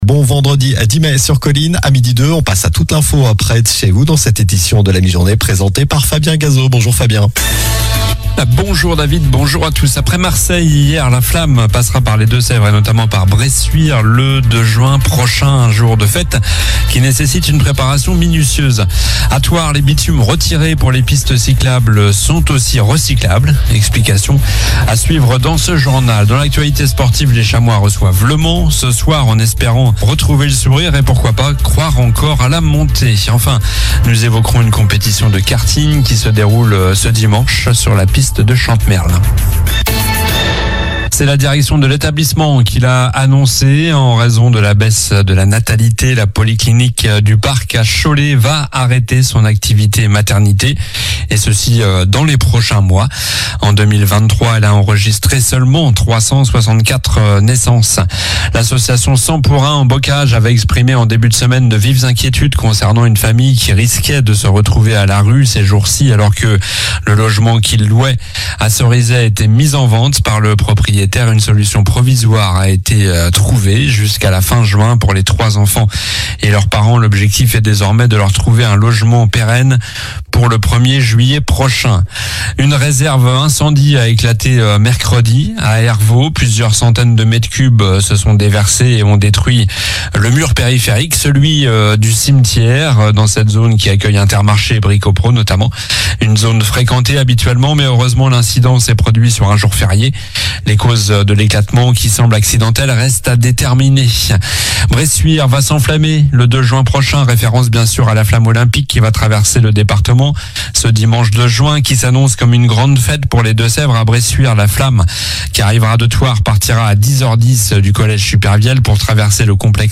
Journal du vendredi 10 mai (midi)